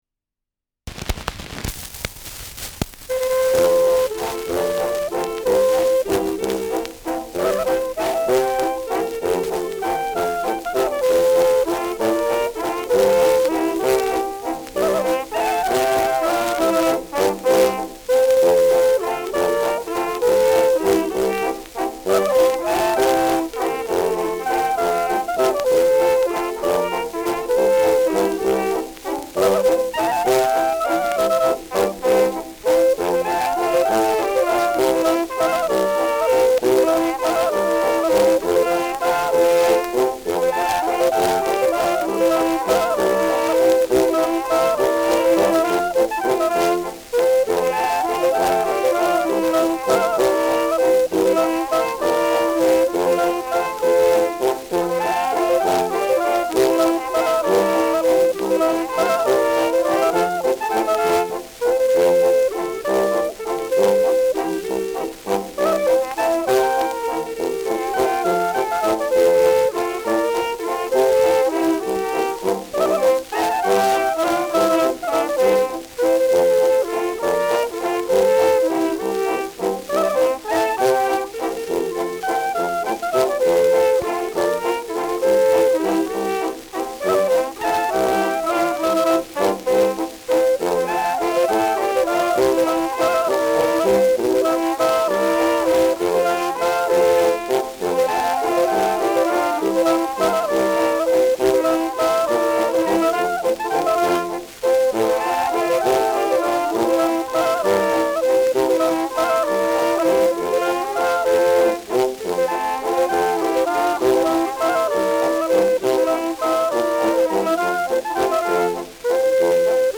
Schellackplatte
Stärkeres Grundrauschen : Gelegentlich leichtes bis starkes Knacken : Verzerrt an lauteren Stellen
Kapelle Die Alten, Alfeld (Interpretation)